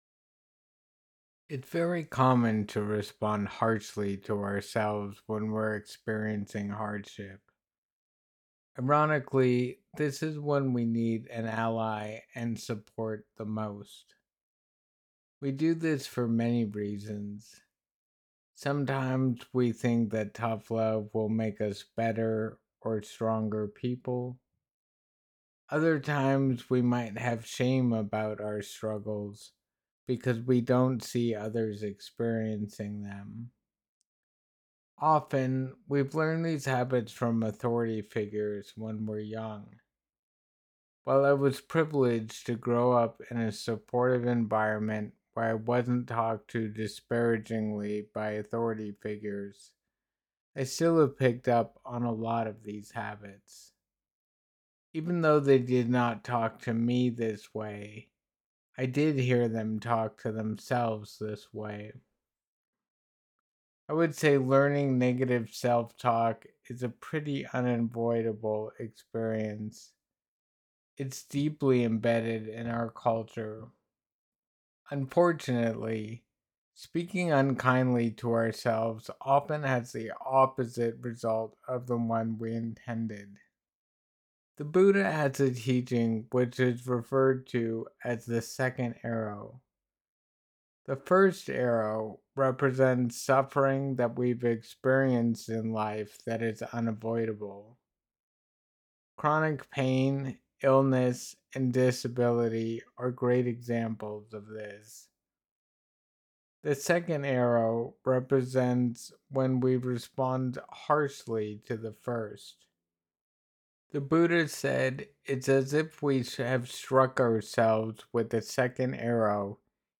It was repurposed and given again at EBEM on 4/12.